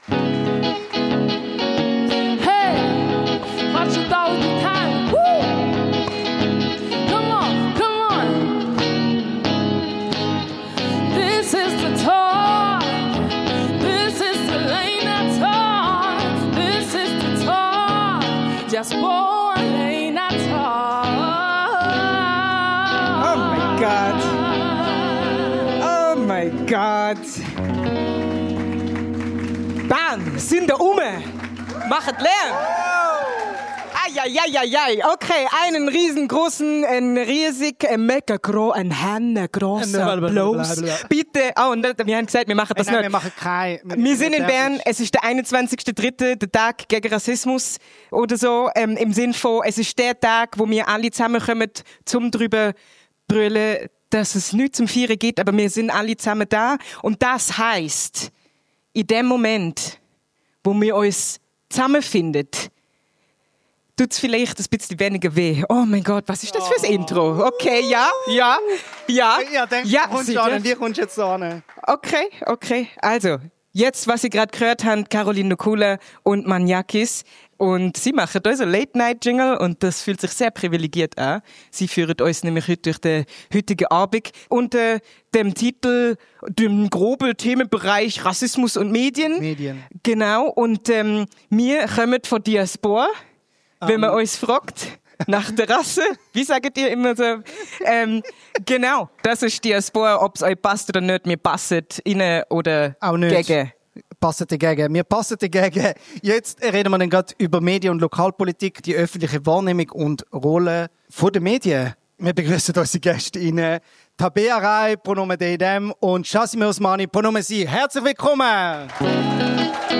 Das ist die zweite von drei Folgen der DIASBOAH Late Night Talks, aufgenommen am 21. März 2025 in der Kornhausbibliothek Bern.